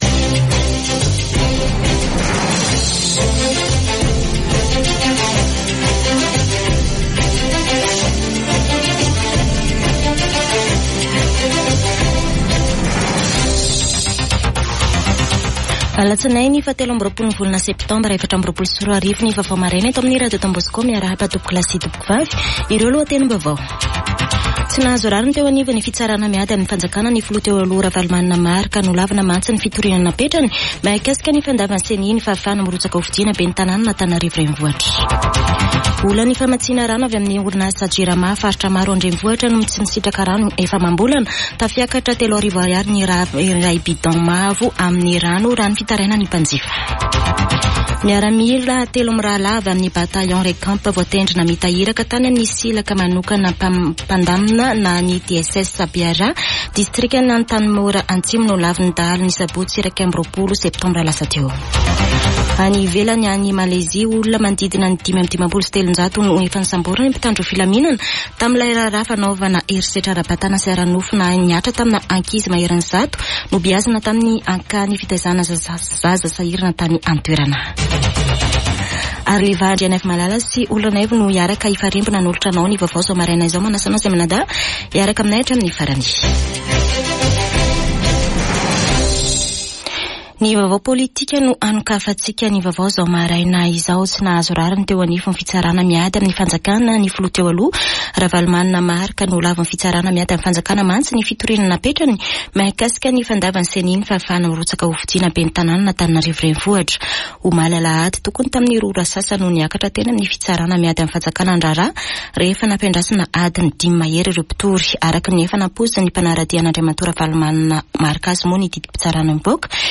[Vaovao maraina] Alatsinainy 23 septambra 2024